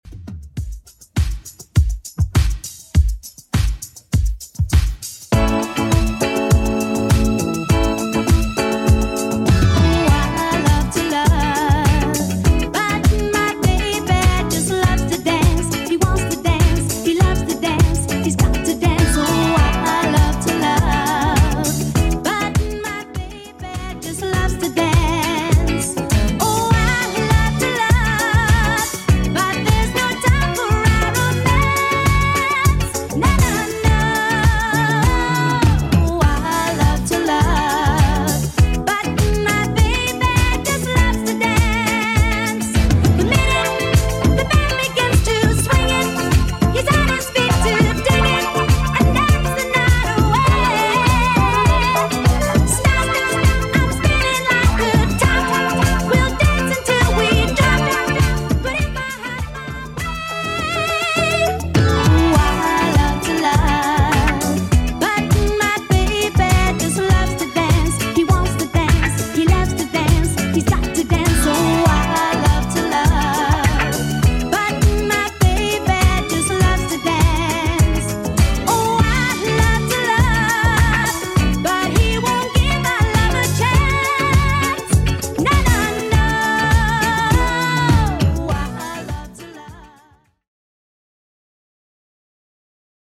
Genre: 80's Version: Clean BPM: 112